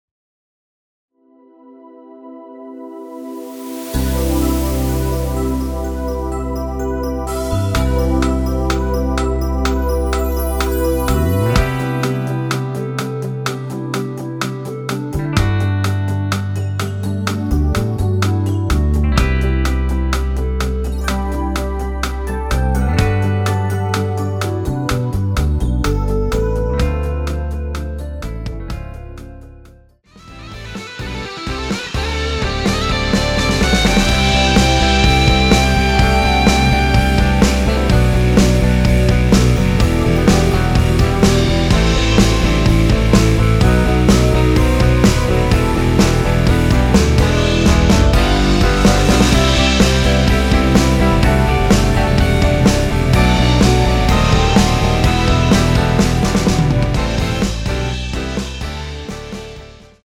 원키에서(-2)내린 멜로디 포함된 MR입니다.(미리듣기 확인)
앞부분30초, 뒷부분30초씩 편집해서 올려 드리고 있습니다.